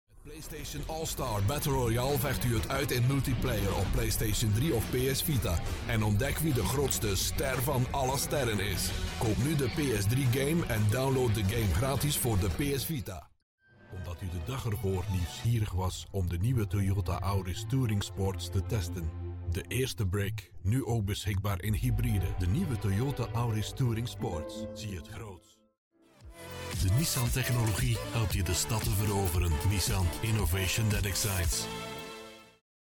Professionelle Sprecher und Sprecherinnen
Belgisch
Männlich